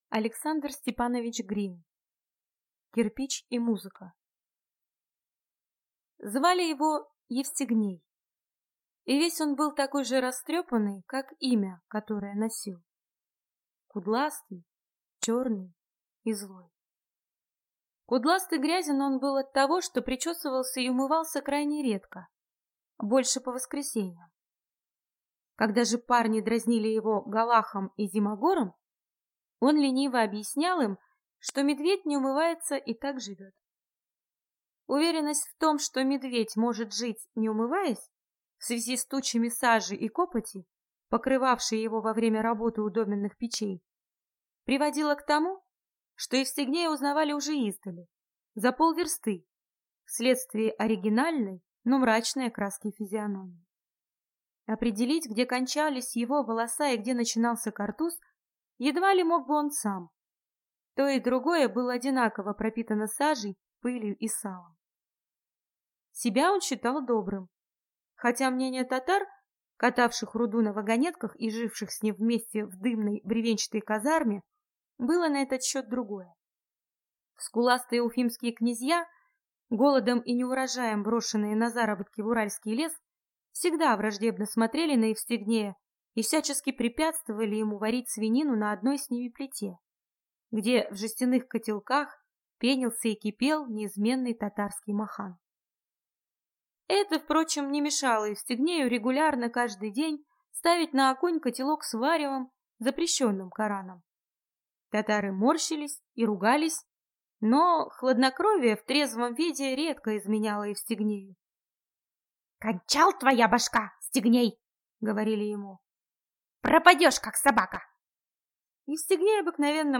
Аудиокнига Кирпич и музыка | Библиотека аудиокниг